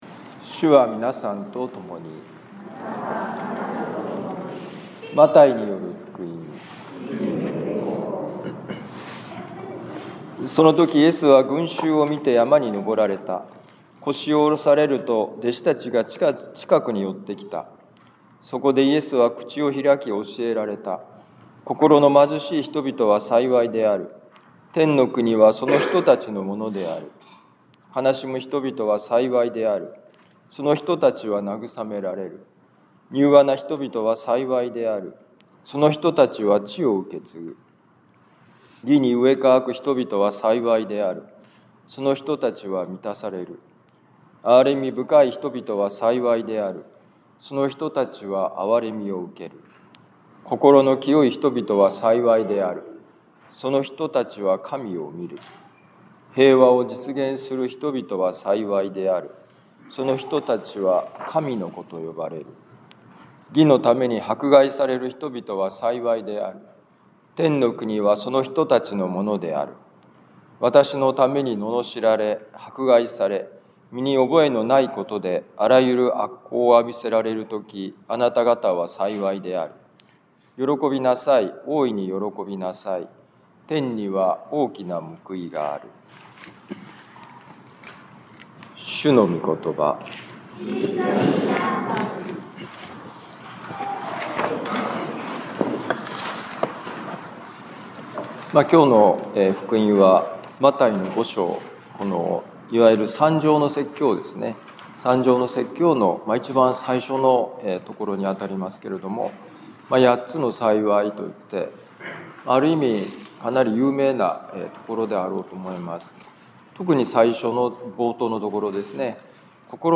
マタイ福音書5章1-12a節「前を向いて歩く幸い」2026年2月1日年間第4主日防府カトリック教会